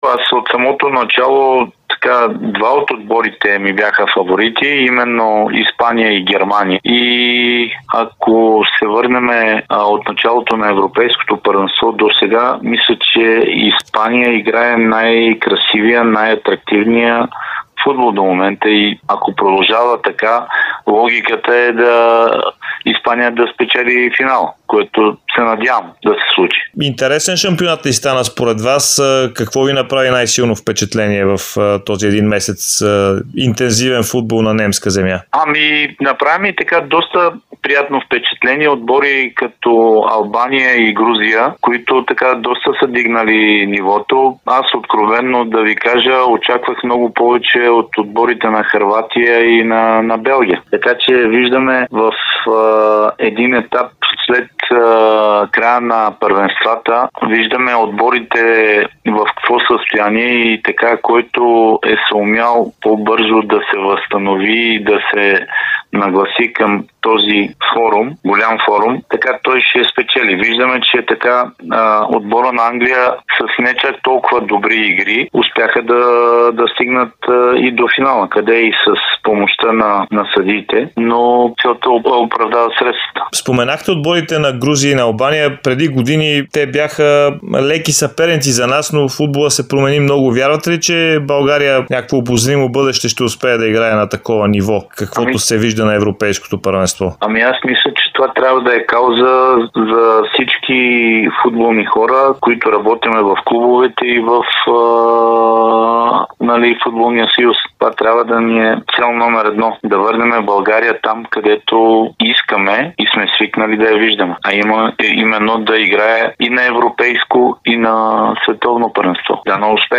Изпълнителният директор на Левски Даниел Боримиров даде интервю пред Дарик радио и dsport часове преди финала на Европейското първенство между Испания и Англия.